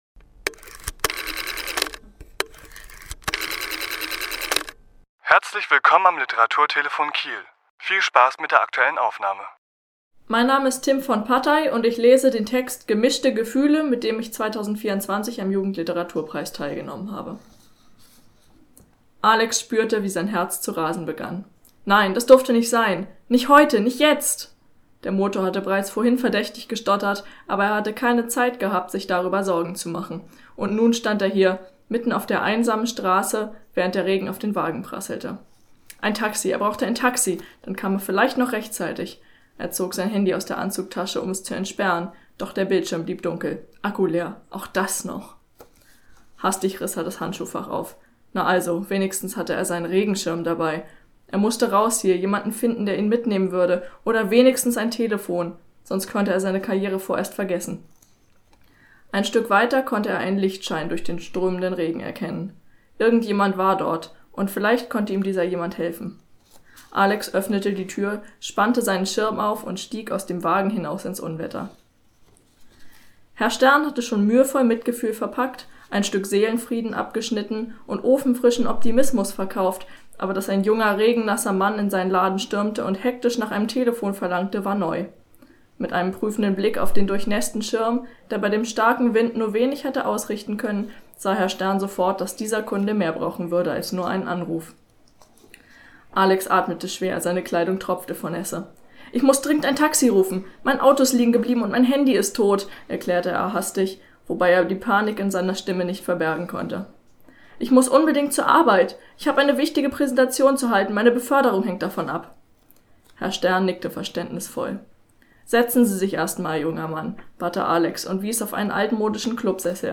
Autor*innen lesen aus ihren Werken
Die Aufnahme entstand im Rahmen der Preisverleihung am 24. April 2024 im Literaturhaus S.-H.